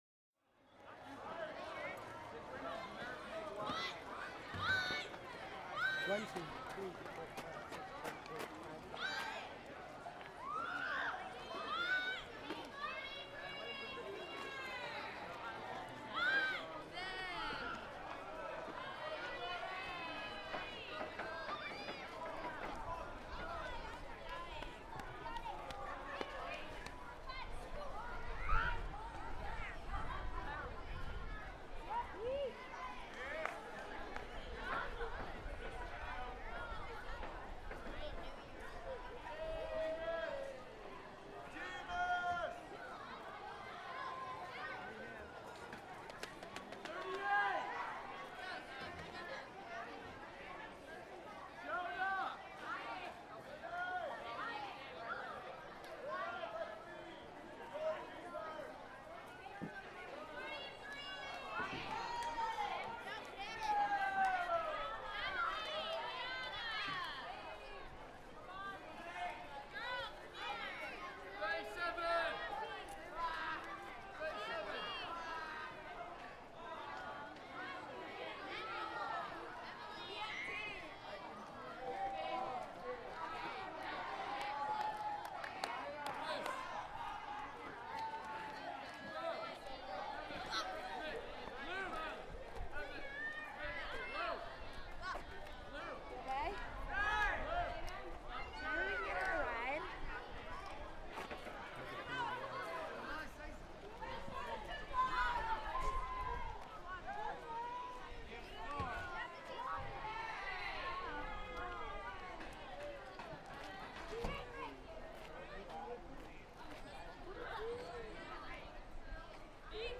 دانلود آهنگ بازی کردن بچه ها از افکت صوتی انسان و موجودات زنده
دانلود صدای بازی کردن بچه ها از ساعد نیوز با لینک مستقیم و کیفیت بالا
جلوه های صوتی